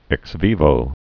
(ĕks vēvō)